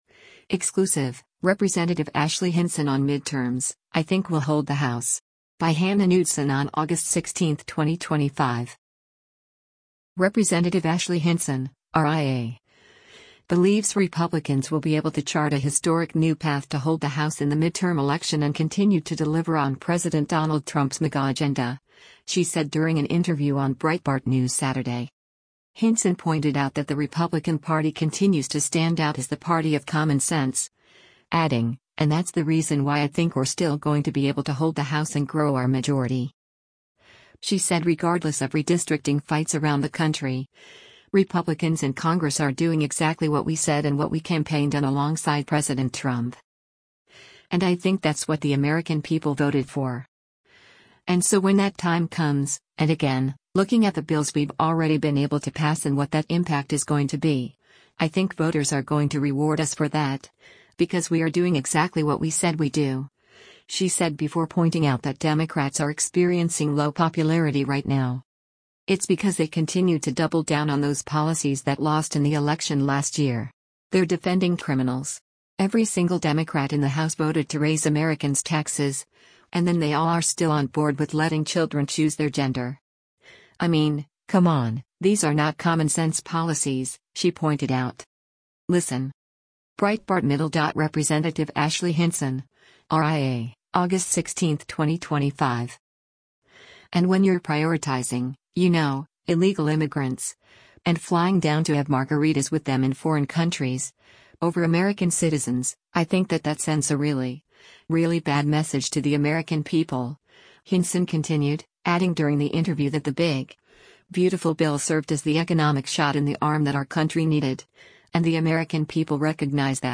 Ashley Hinson speaks
Rep. Ashley Hinson (R-IA) believes Republicans will be able to chart a historic new path to hold the House in the midterm election and continue to deliver on President Donald Trump’s MAGA agenda, she said during an interview on Breitbart News Saturday.